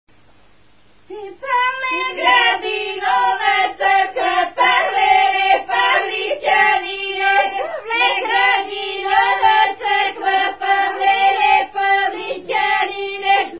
музикална класификация Песен
размер Две четвърти
фактура Двугласна
начин на изпълнение Група (на отпяване)
битова функция На хоро
фолклорна област Средна Западна България
начин на записване Магнетофонна лента